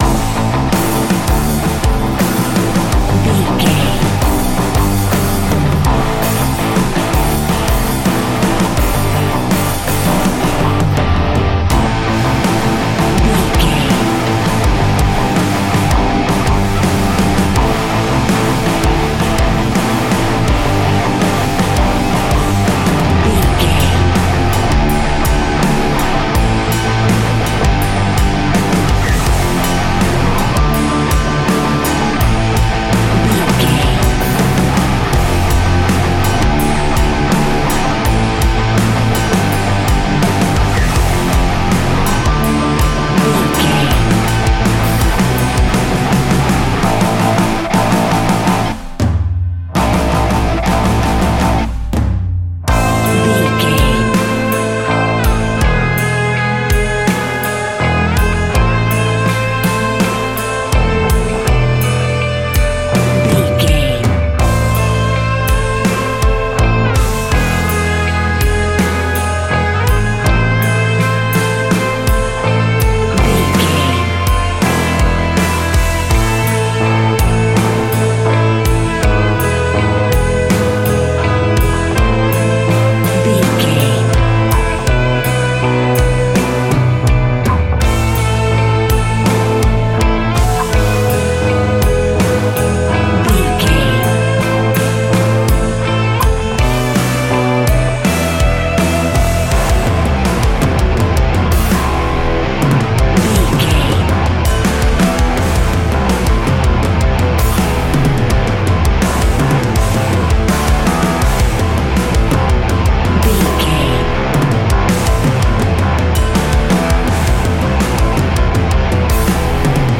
Ionian/Major
A♭
hard rock